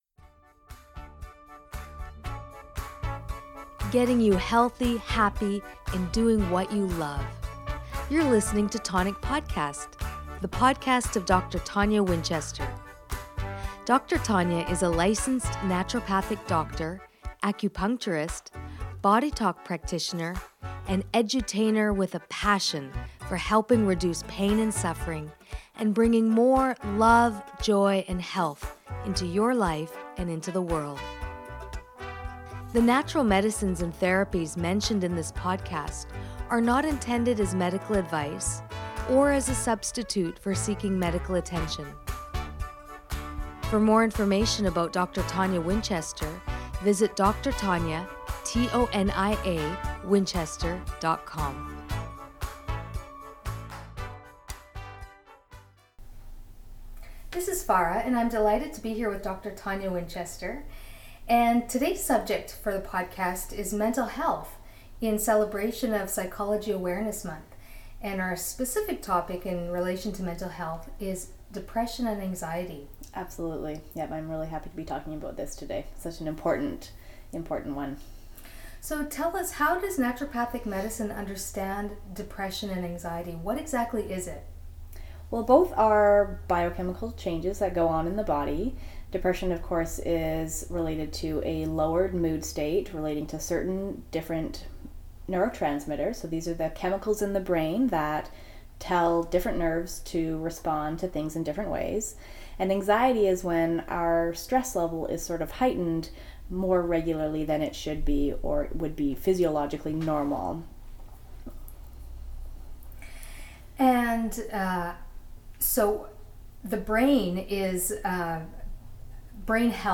WEST MY FRIEND Interview by